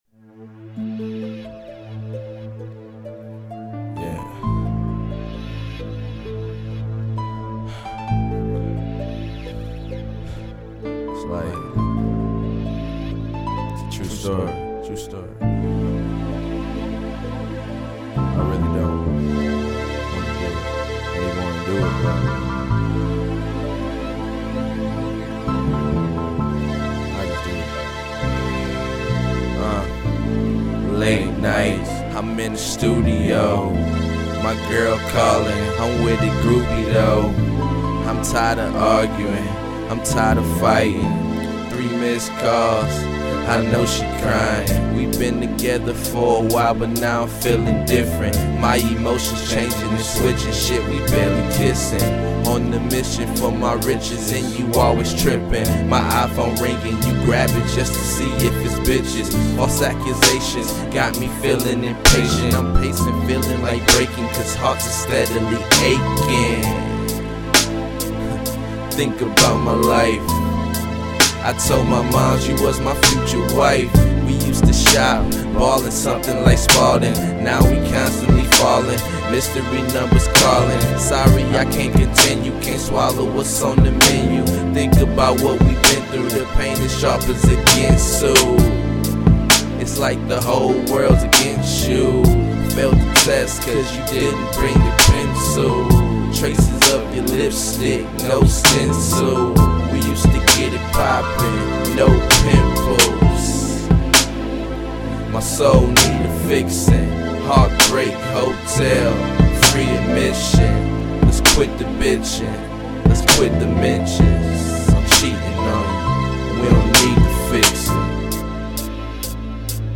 is an unconventional music duo from Milwaukee, WI.